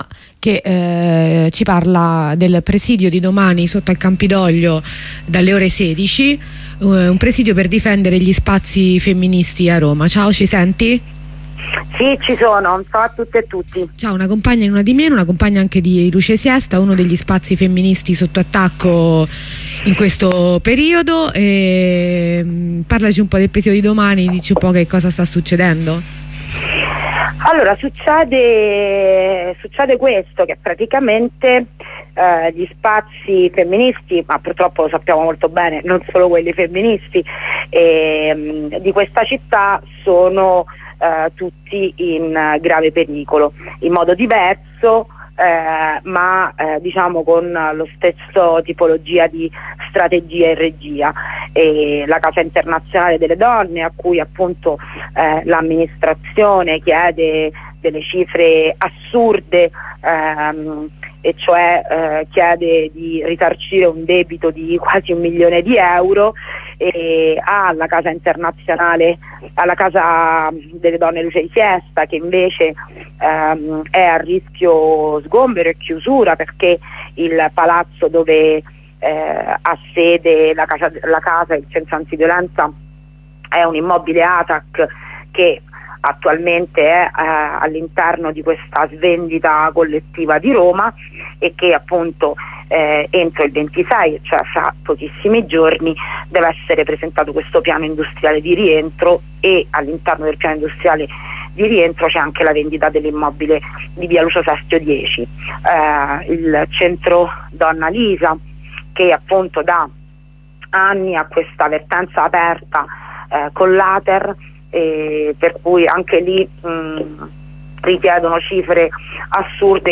Corrispondenza da Verona: la compagna ci racconta l'apertura del corteo